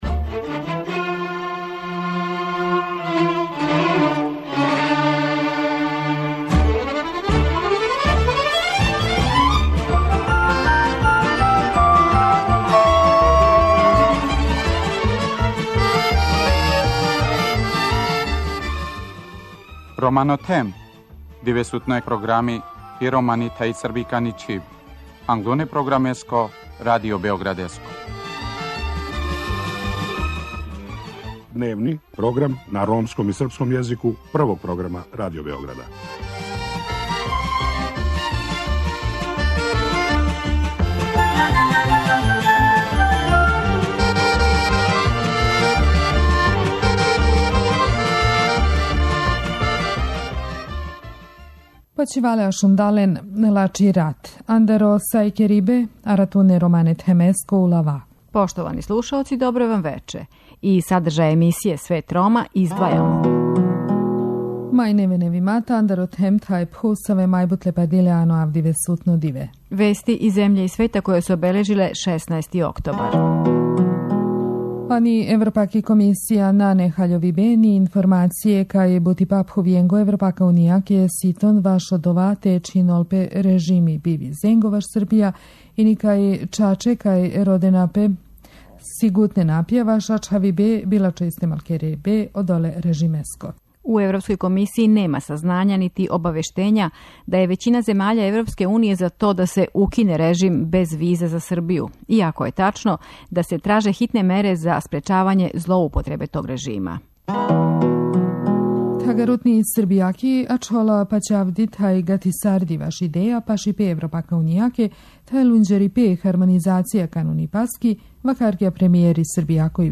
Са замеником заштитника грађана Гораном Башићем разговарамо о недавно усвојеним изменама закона о ванпарничном поступку.